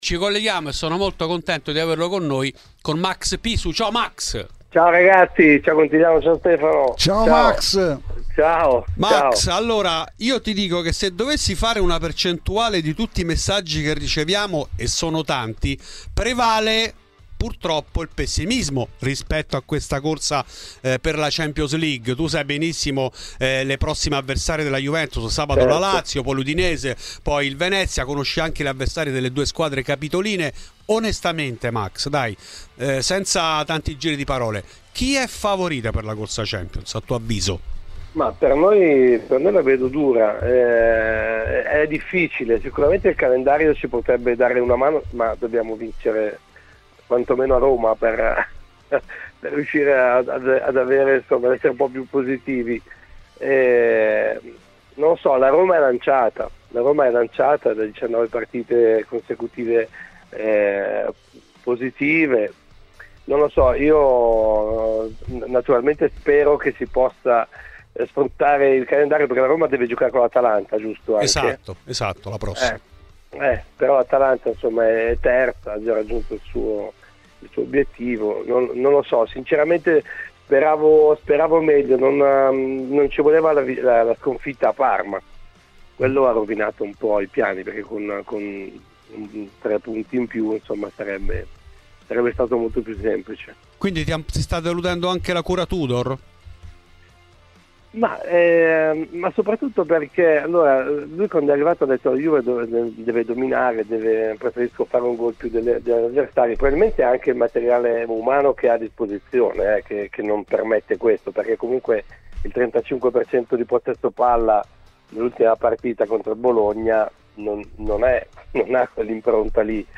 Max Pisu a RBN: l'attore comico, noto tifoso della Juve, parla della lotta Champions, di Tudor, Conte, gli errori di Giuntoli e di Del Piero